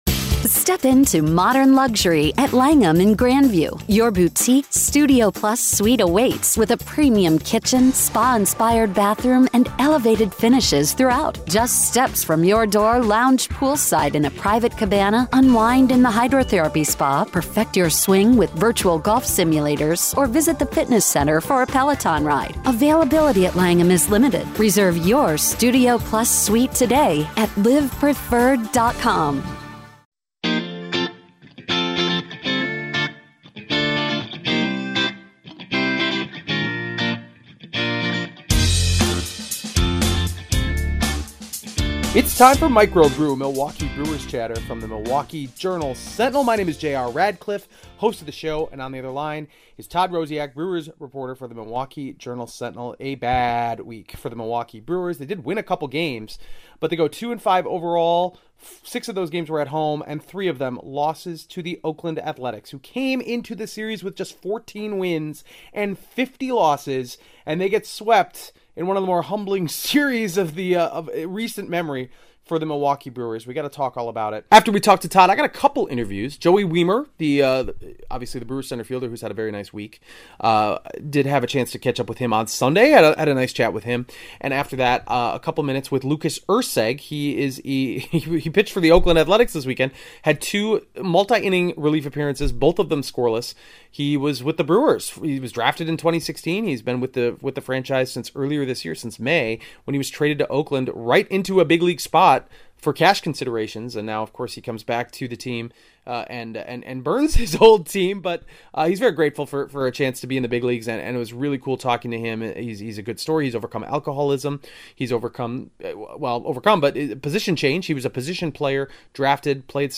Nightmare weekend against floundering Oakland and a conversation with Joey Wiemer (06.11.2023)